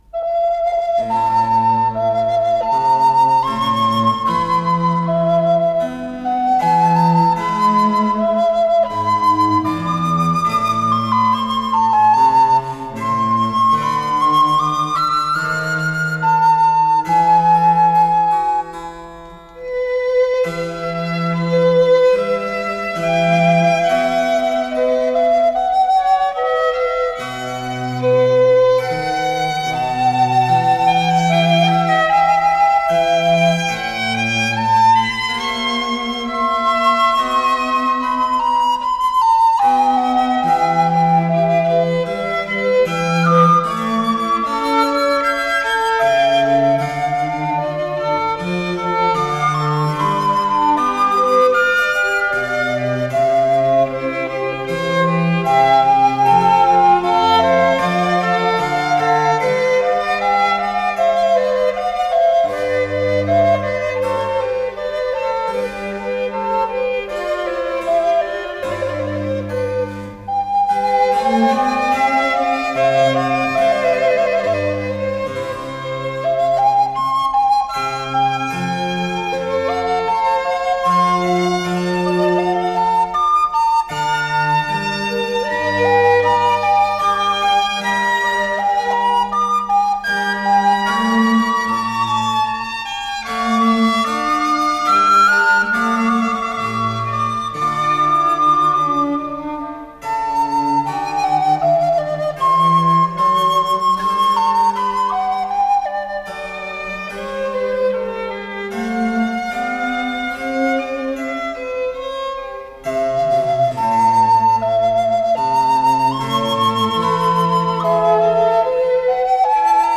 ДКС-16495 — Трио-соната для продольной флейты, скрипки и бассо-континуо (клавесин, виолончель) — Ретро-архив Аудио
продольная флейта
скрипка Алексей Любимов - клавесин
виолончель
Из серии "Музыкальные упражнения", ля минор